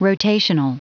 Prononciation du mot rotational en anglais (fichier audio)